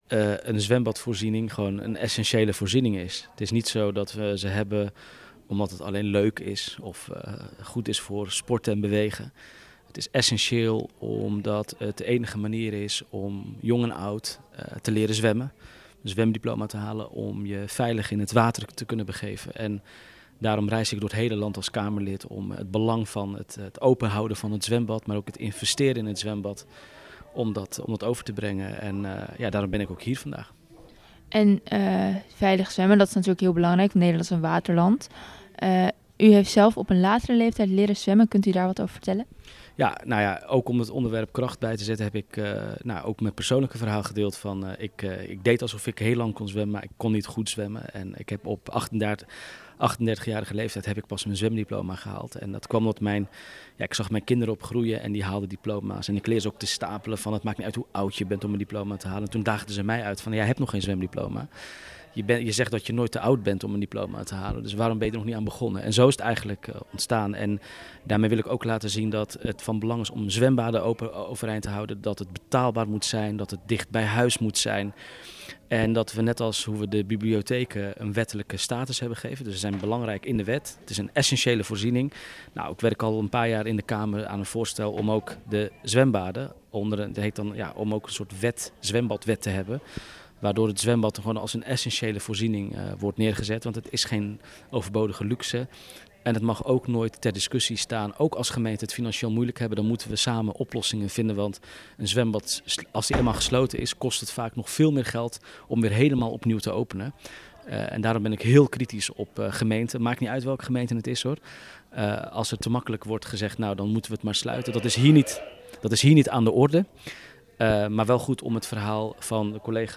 Mohammed Mohandis, Tweede Kamerlid voor GroenLinks-Pvda, over het belang van zwemmen.